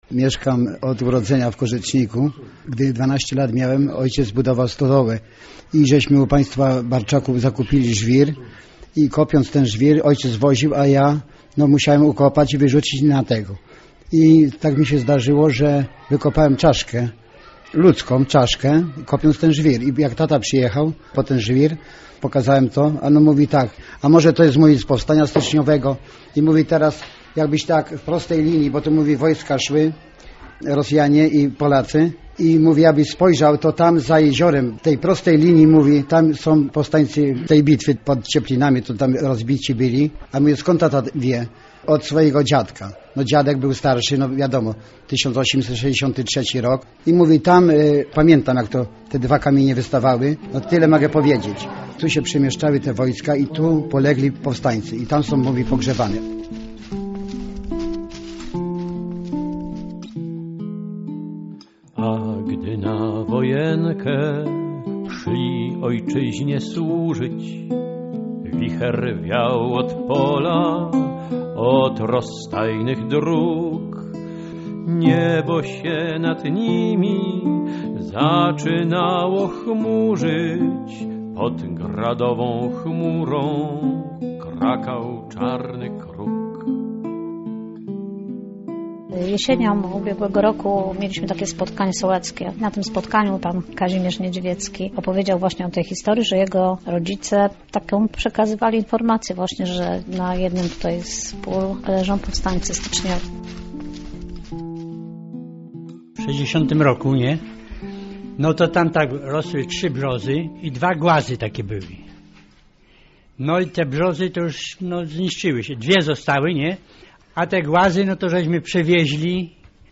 Na Korzeckiej Ziemi - reportaż - Radio Poznań